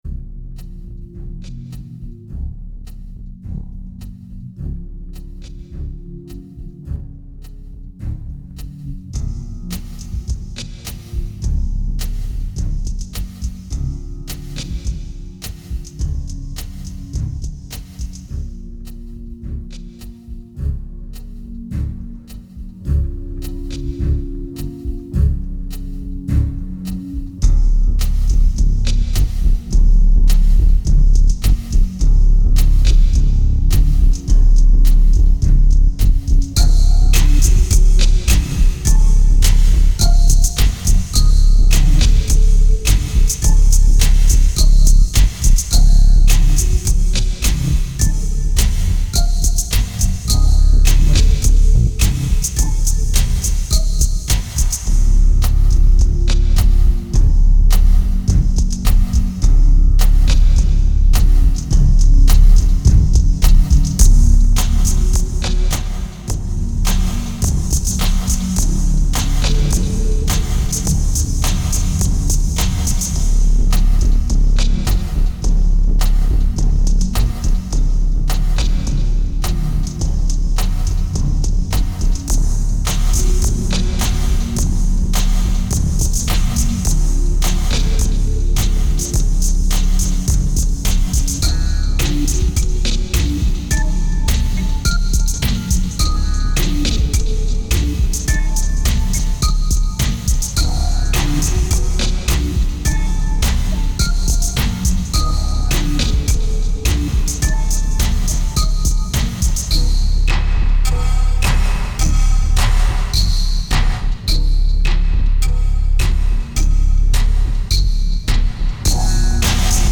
Tight and progressive, it seems to flow out more naturally.
2223📈 - -36%🤔 - 105BPM🔊 - 2008-11-02📅 - -305🌟